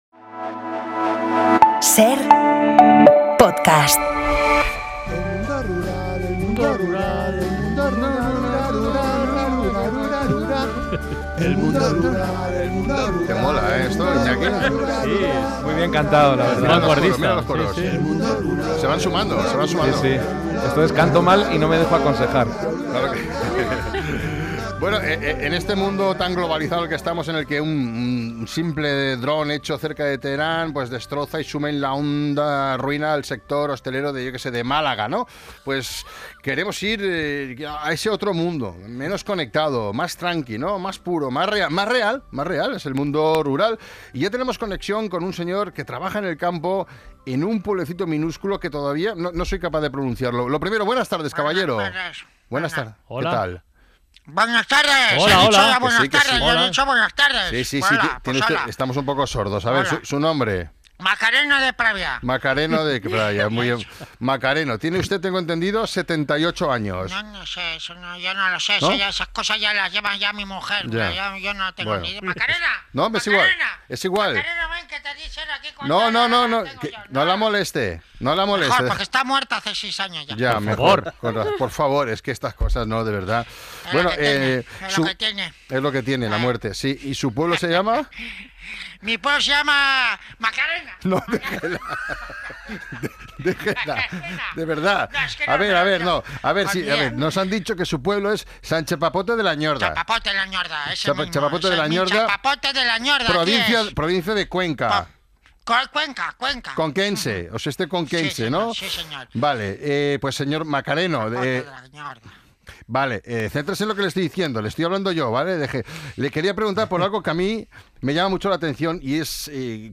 También hablamos con un campesino conquense que nos muestra la increíble cantidad de maquinaria que necesita para vivir.